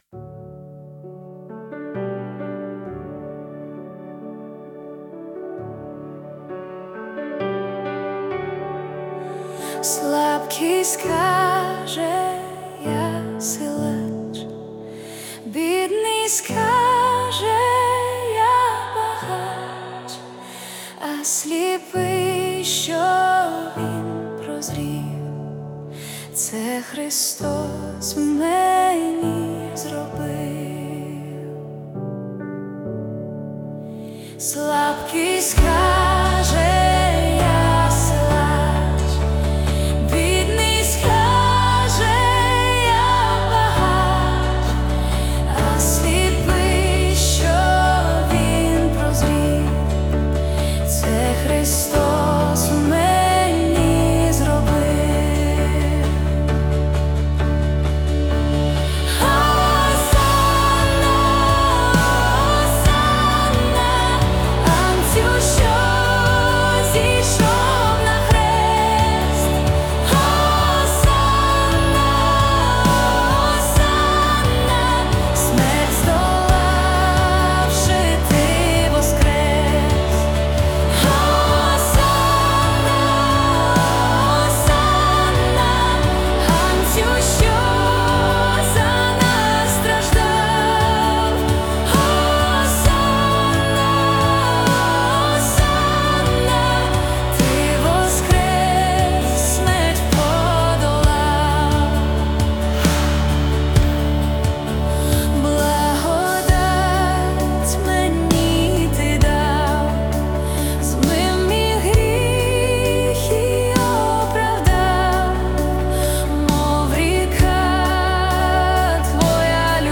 песня ai
13 просмотров 42 прослушивания 0 скачиваний BPM: 72 4/4